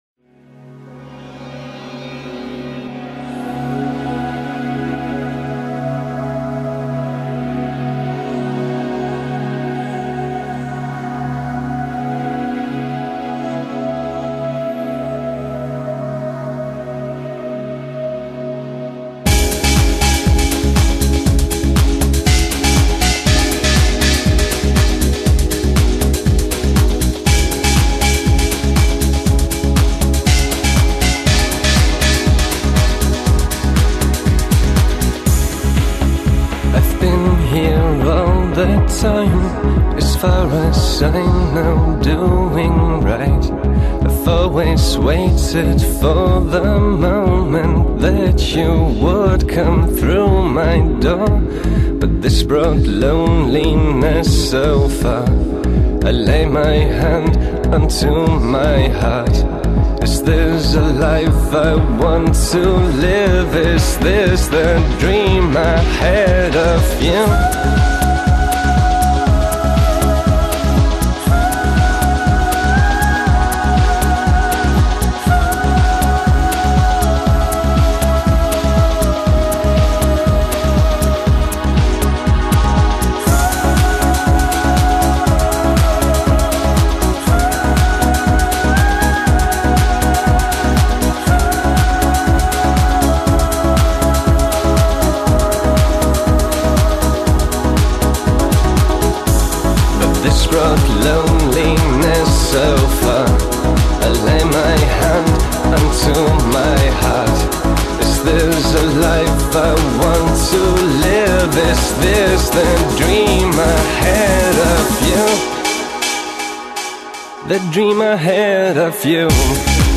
Жанр: House/Trance Альбом